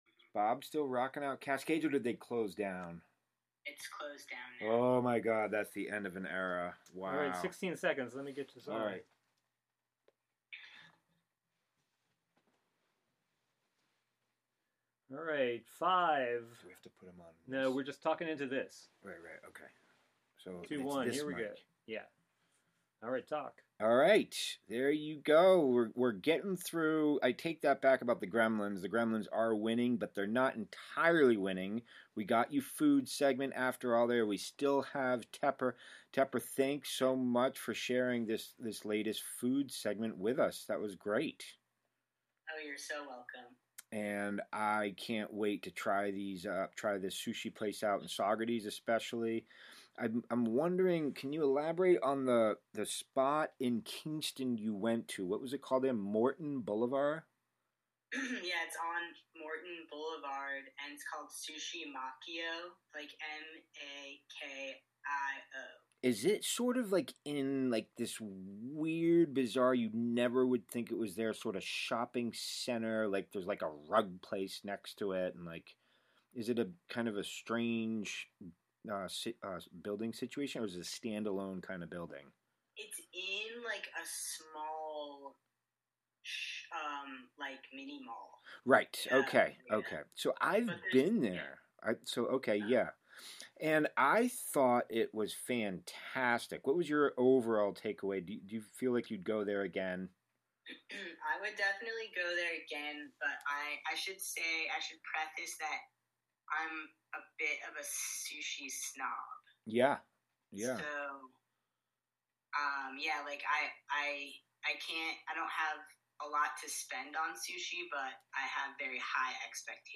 Wave Farm | Prime Jive: Monday Afternoon Show- Live from Housatonic, MA